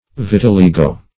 Vitiligo \Vit`i*li"go\, n. [L., a kind of tetter, fr. vitium